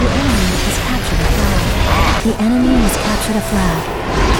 The tools: recorded with Fraps; splitted in two parts with Virtualdub; encoded with Vidcoder normal preset; trying to adjust the audio with Audacity 2.0. The issue: the audio sounds like metallic, I don’t know how to better explain it but it’s different from the the original gameplay.
The tools: recorded with Fraps; splitted in two parts with Virtualdub; encoded with Vidcoder normal preset; trying to adjust the audio with Audacity 2.0.